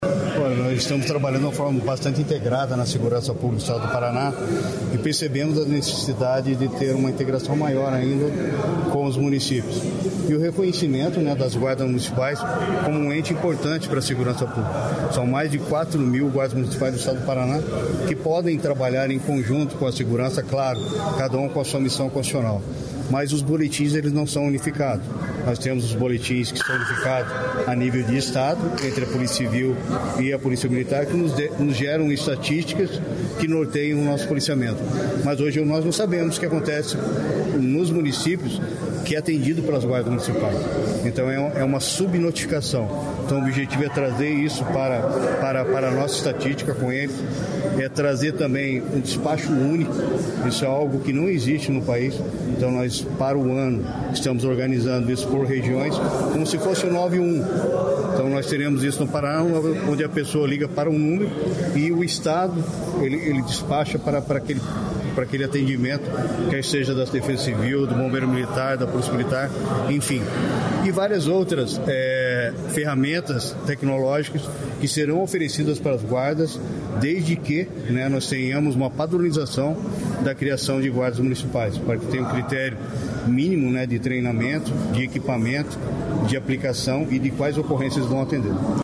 Sonora do secretário de Segurança Pública, Hudson Leoncio Teixeira, sobre a integração entre forças de segurança